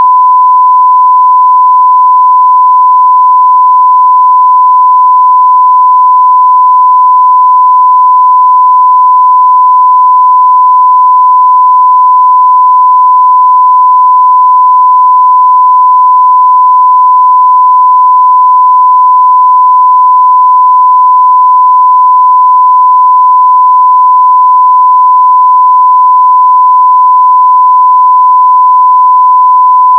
Bei diesem Test-Ton handelt es sich um einen konstanten 1004Hz Sinus-Ton bei 0dB (Vorsicht: Laut!):
Den Milliwatt-Test-Ton erzeugen
Achtung: Der Test-Ton ist sehr laut, daher nicht das Headset oder den Hörer direkt ans Ohr halten!
Milliwatt.wav